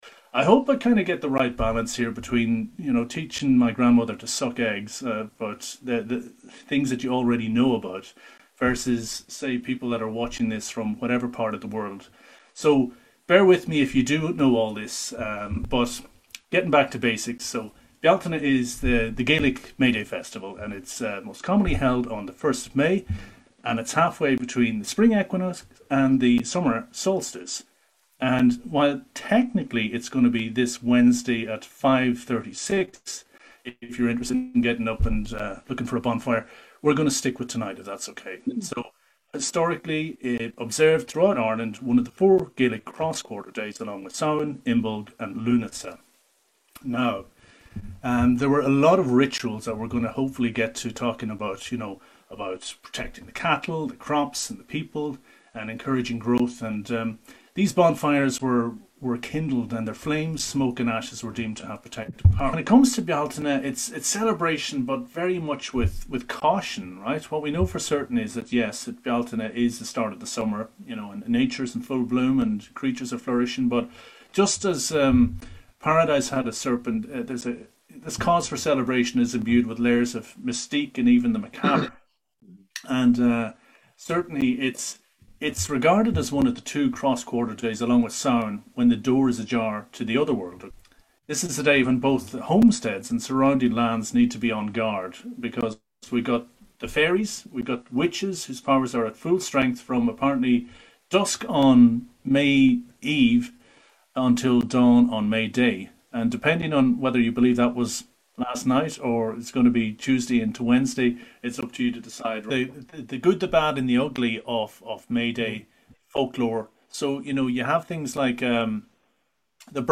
Recorded via Facebook Live on the 1st May 2021.